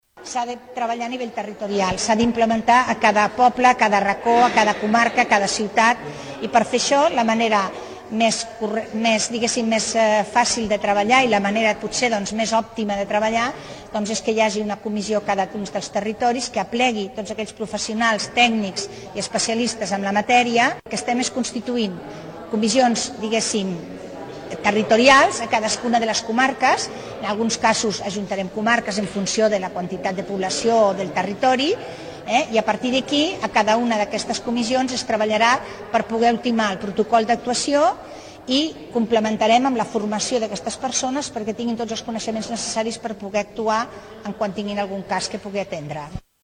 En dóna més detalls Dolors Gordi, secretaria del departament de benestar i família de la generalitat.